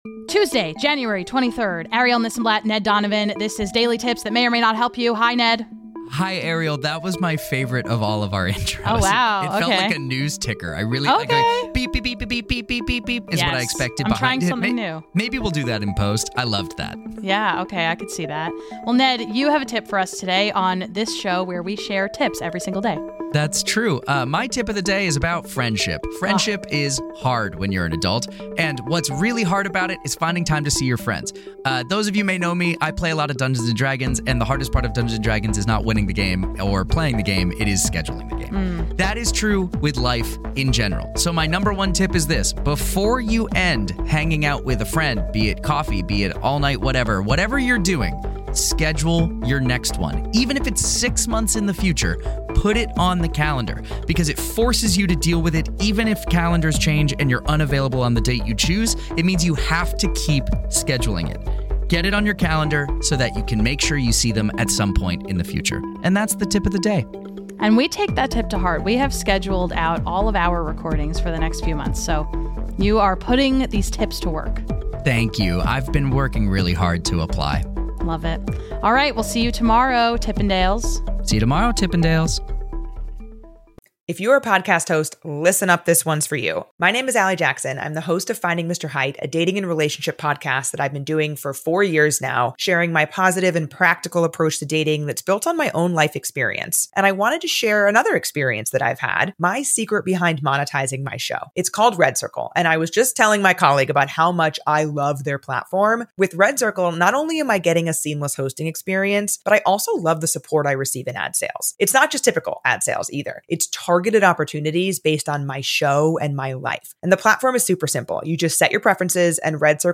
Music is “Makeshift News Team” by Marcus Thorne Bagala